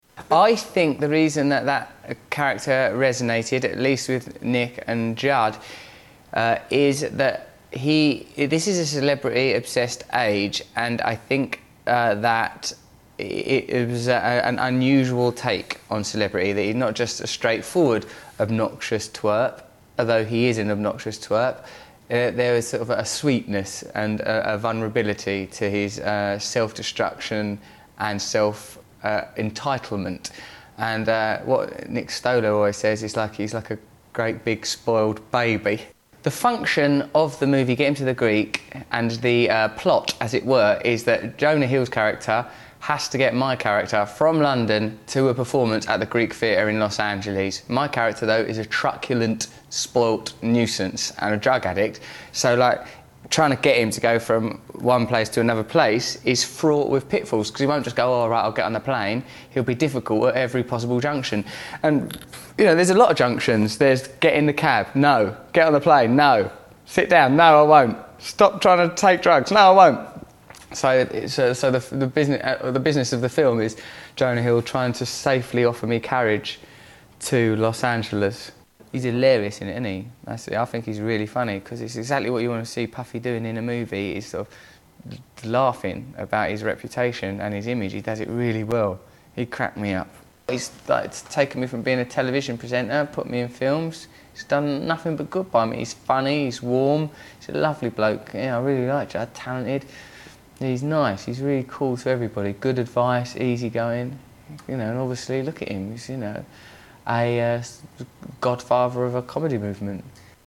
Russell Brand Interview